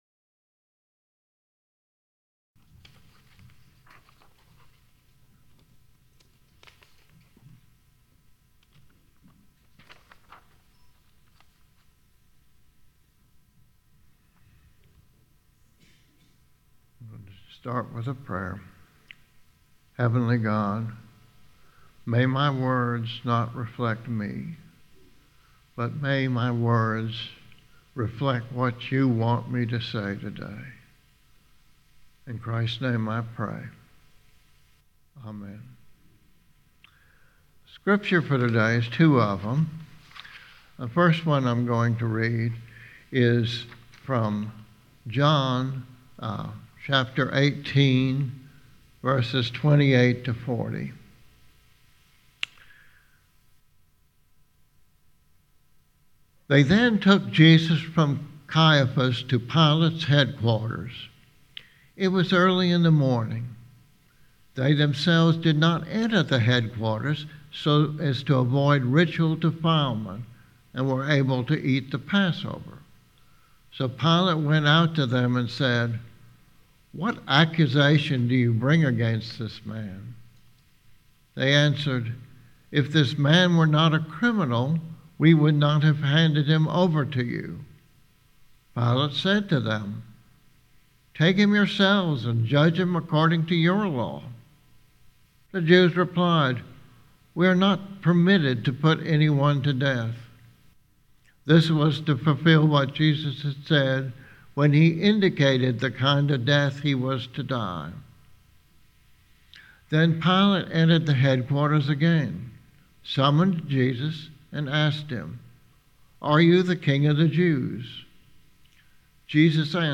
Sermons Archive - GracePoint at Mt. Olive